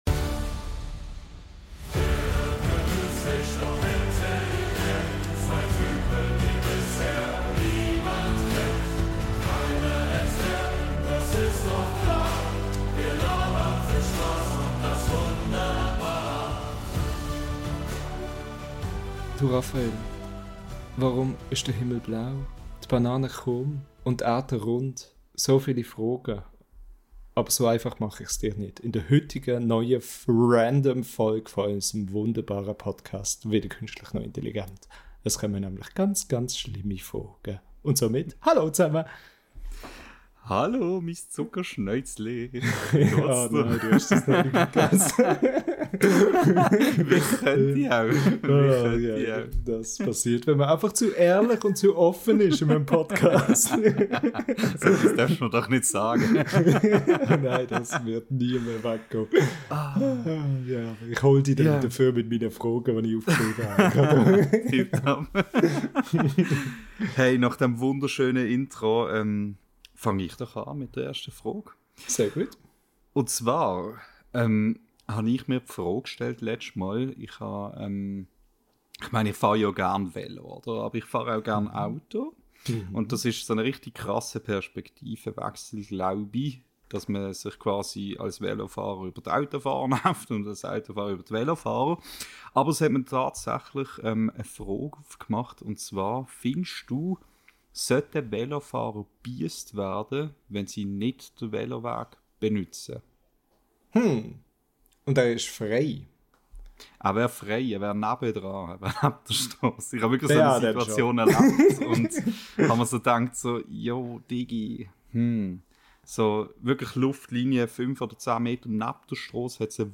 Wieder einmal Zeit für völlig sinnfreie Fragen, noch absurdere Antworten und ja, auch eine Prise Ekel ist dabei. Wenn ihr bereit seid für eine neue Portion Wahnsinn aus unserem schweizerdeutschen Podcast, dann hört rein!